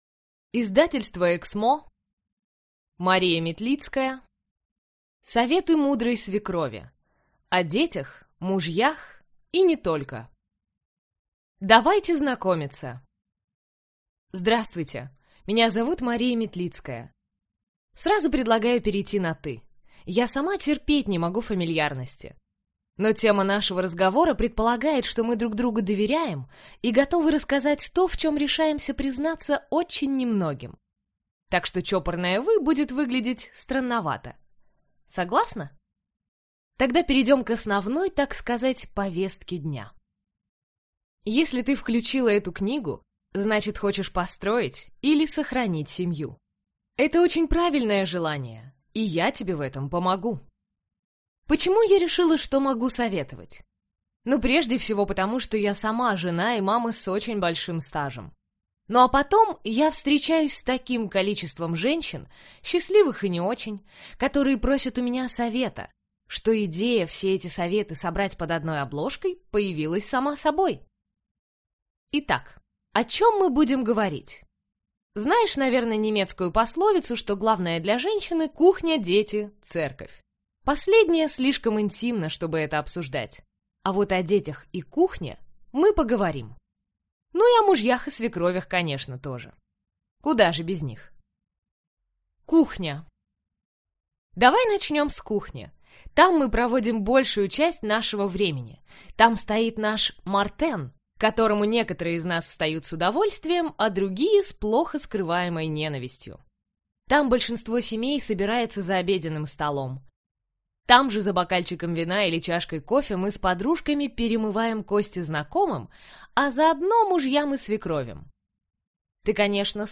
Аудиокнига Советы мудрой свекрови. О детях, мужьях и не только…